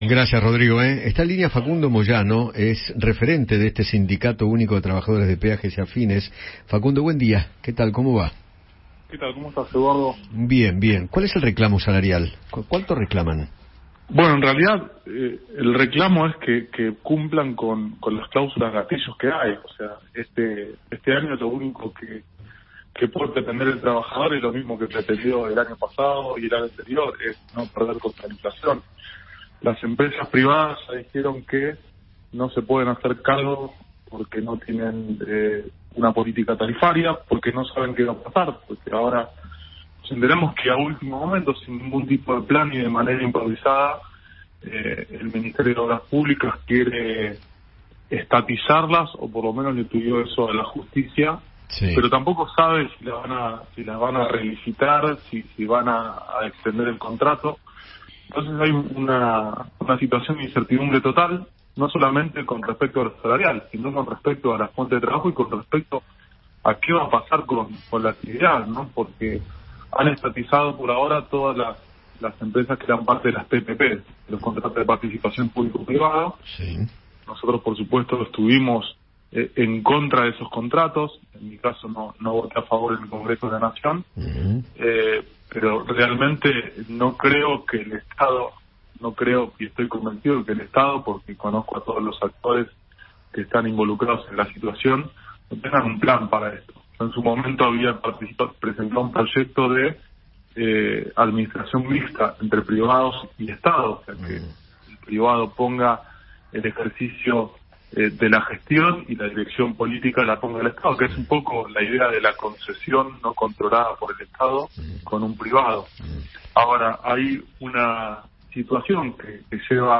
Facundo Moyano, referente del Sindicato Único de Trabajadores de los Peajes y Afines (SUTPA), conversó con Eduardo Feinmann sobre el paro general que inició este gremio y analizó la coyuntura económica del país.